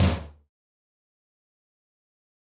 Tom Groovin 3.wav